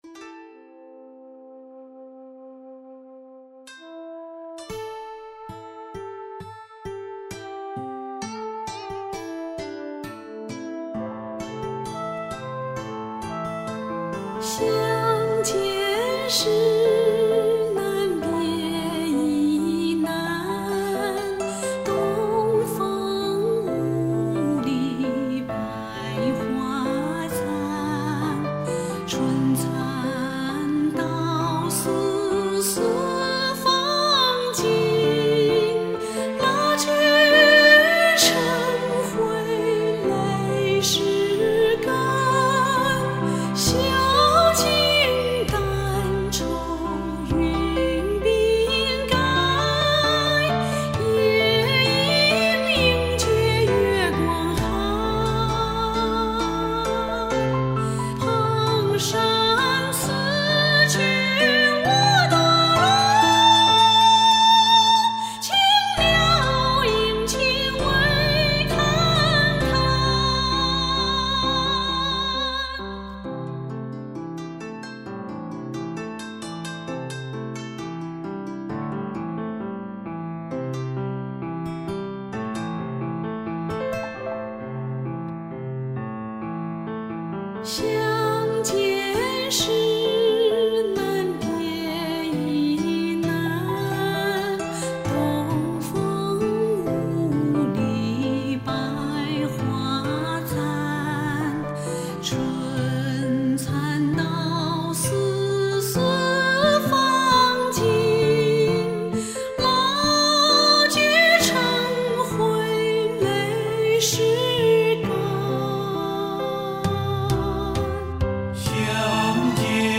旋律起伏迭荡又不失幽雅，配器老到